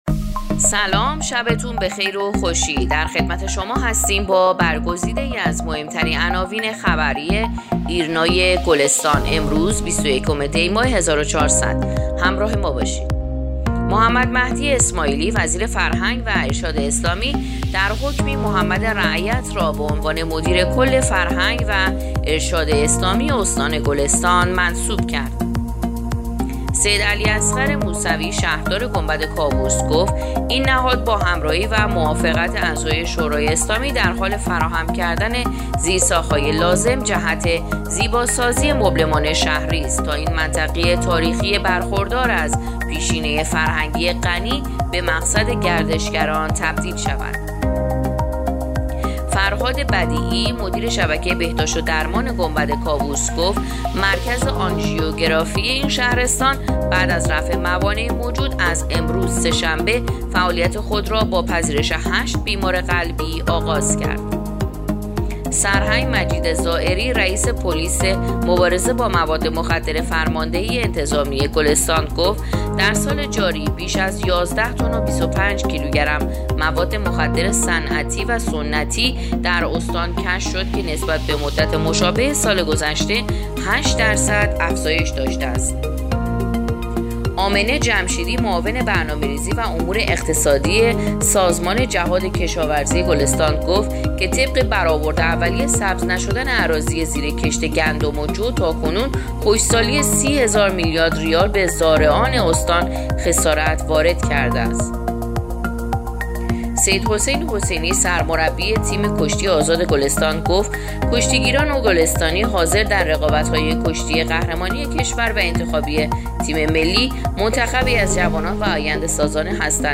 پادکست/ اخبار شبانگاهی بیست و یکم دی ماه ایرنا گلستان